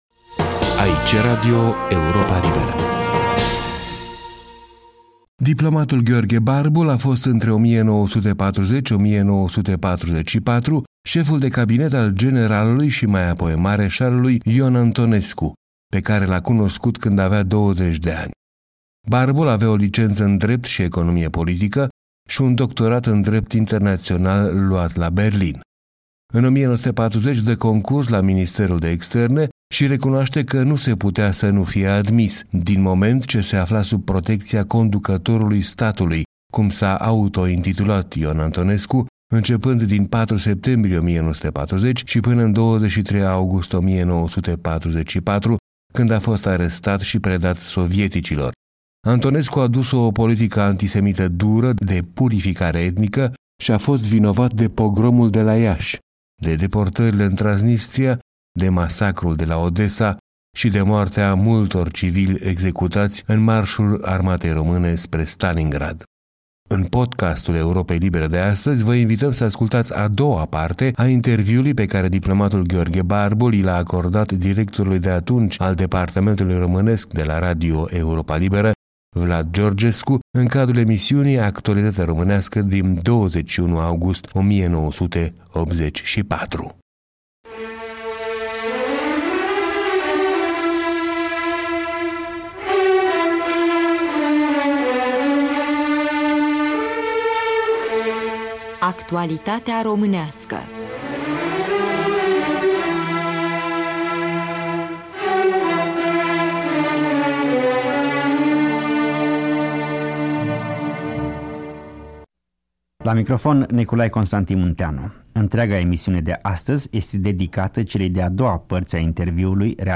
A doua parte a interviului pe care diplomatul Gheorghe Barbul i l-a acordat directorului de atunci al departamentului românesc de la radio Europa Liberă, Vlad Georgescu, în cadrul emisiunii „Actualitatea românească” în august 1984.